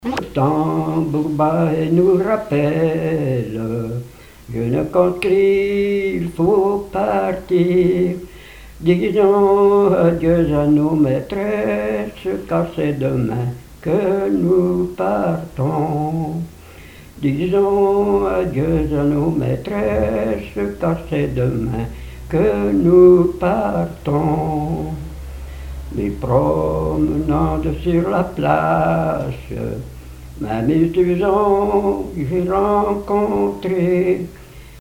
Chant de conscrit
Genre strophique
Pièce musicale inédite